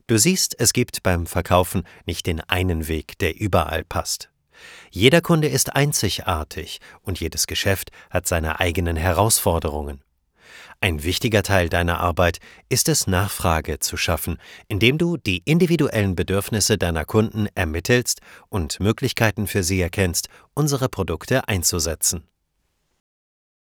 Male
Authoritative, Confident, Friendly, Natural
A young, bright and fresh voice, that's warm, welcoming and full of character.
1 Imagefilm-Google.mp3
Microphone: Neumann TLM 103